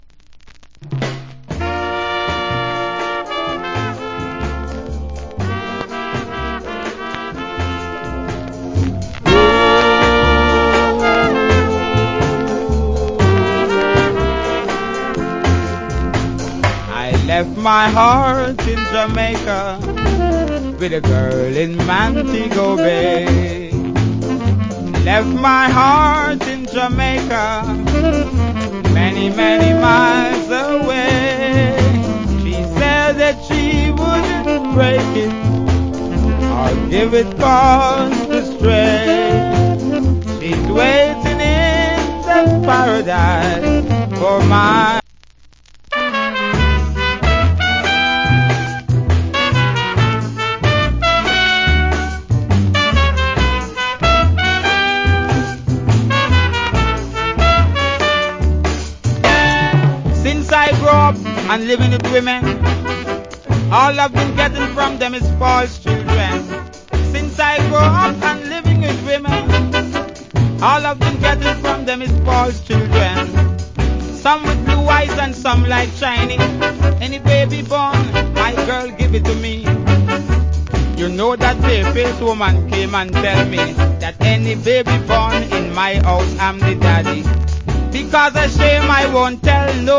Cool Calypso Vocal.